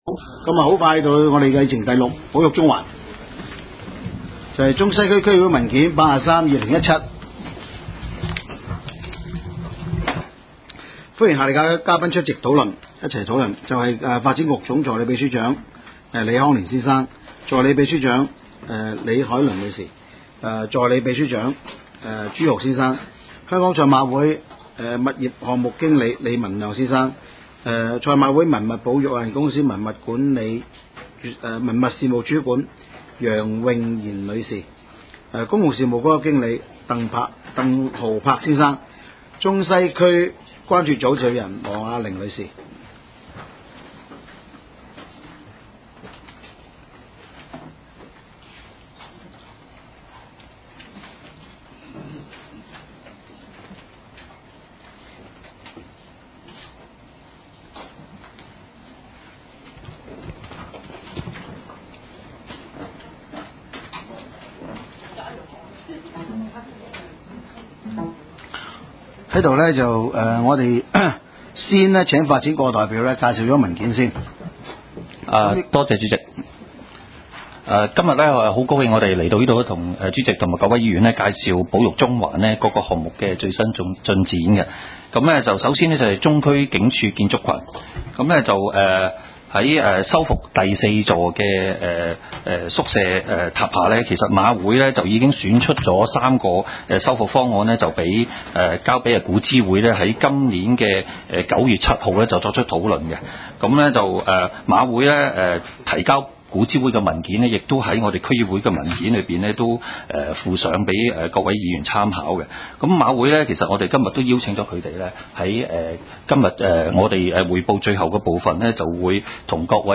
区议会大会的录音记录